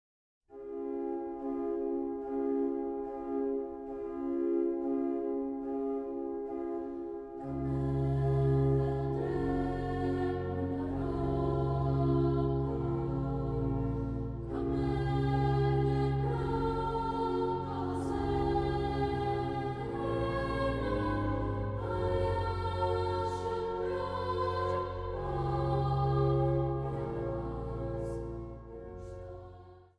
Diverse koren en artiesten.
Instrumentaal
Zang